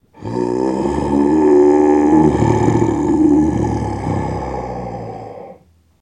Human Vocals
Monster Growl Dinosaurs and Relic Human Voice Pitched